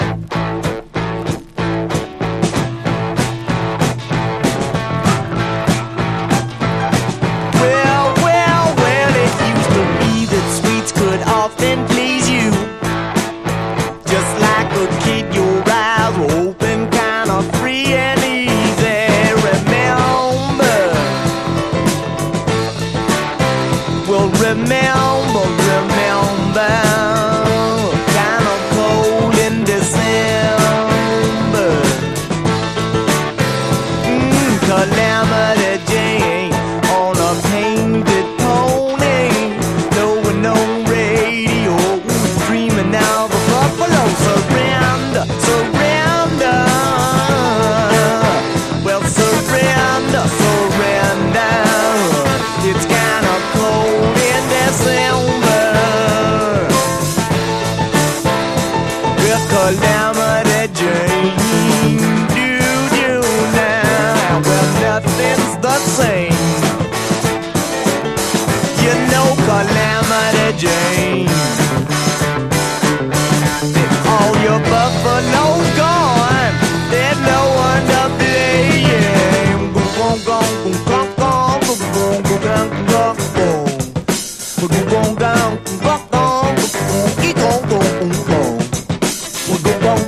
陽だまりメロウ・フォークS.S.W.必携盤！